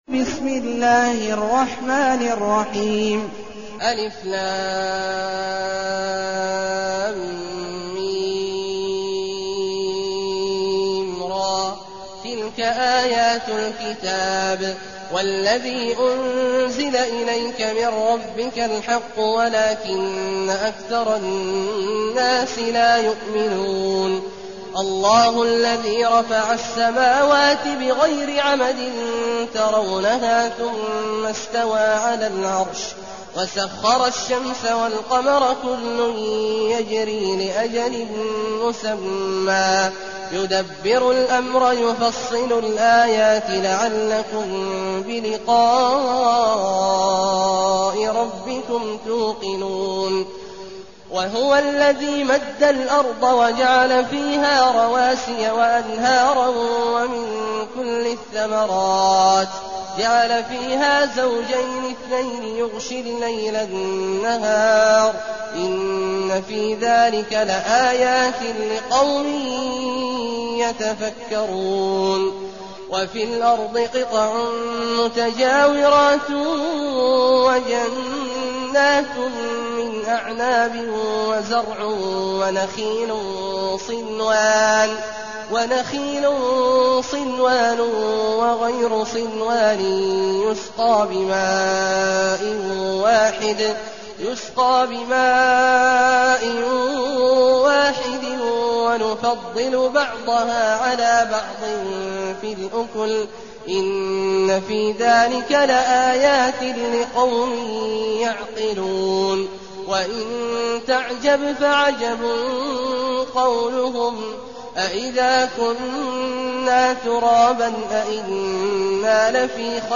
المكان: المسجد النبوي الشيخ: فضيلة الشيخ عبدالله الجهني فضيلة الشيخ عبدالله الجهني الرعد